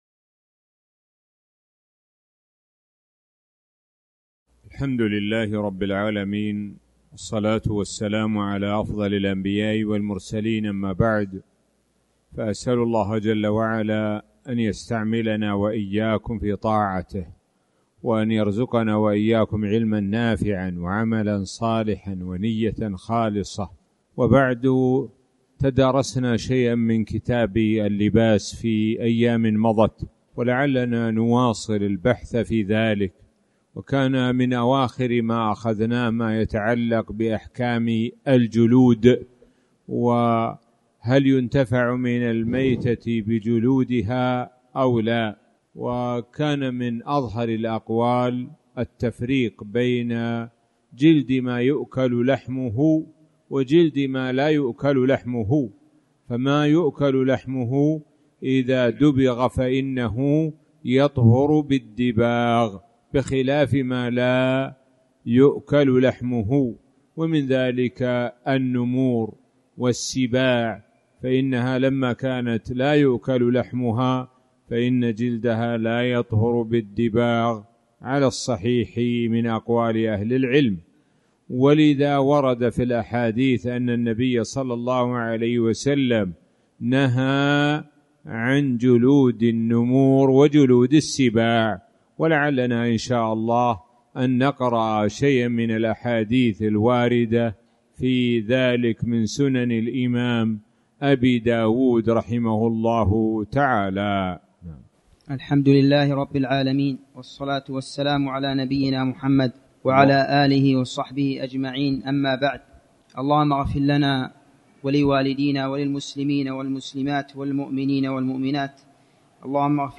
تاريخ النشر ٣ ذو القعدة ١٤٤٠ هـ المكان: المسجد الحرام الشيخ: معالي الشيخ د. سعد بن ناصر الشثري معالي الشيخ د. سعد بن ناصر الشثري باب اللباس